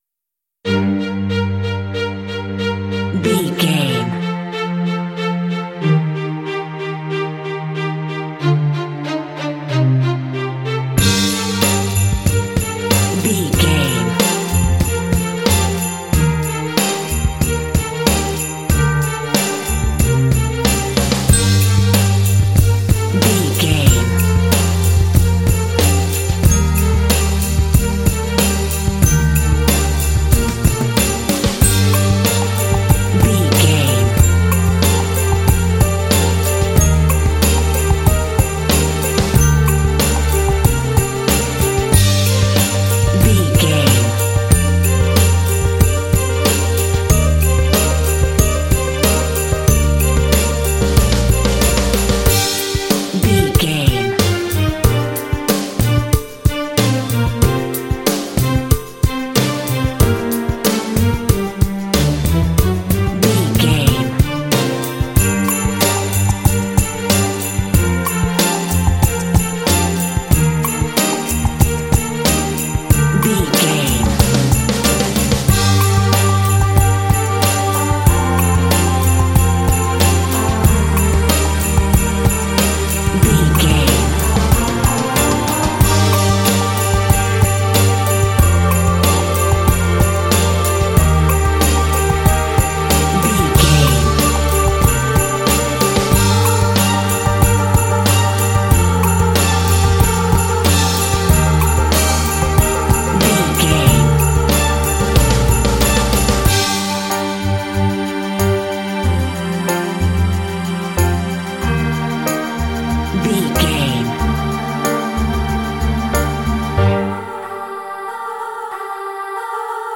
Aeolian/Minor
romantic
proud
epic
drums
strings
vocals
christmas